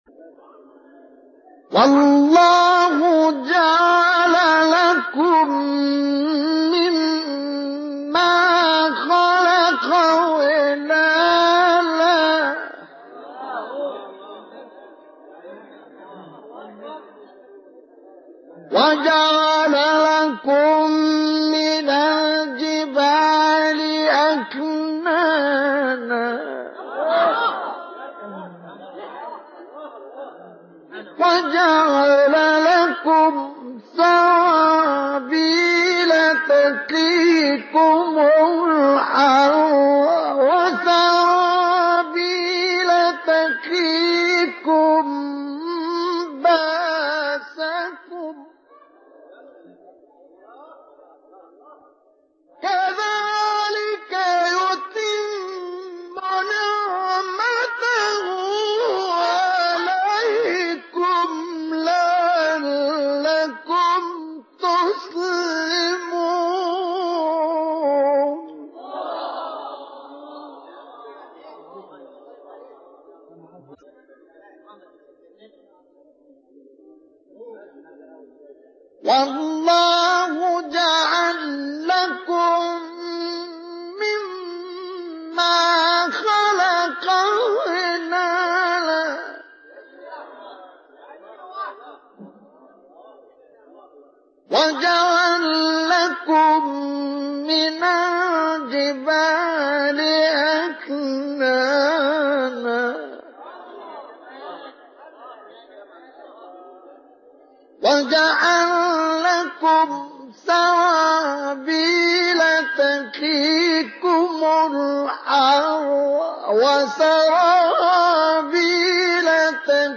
گروه شبکه اجتماعی: فرازهای صوتی از تلاوت قاریان برجسته مصری را می‌شنوید.
مقطعی از مصطفی اسماعیل/ سوره انفال در مقام نهاوند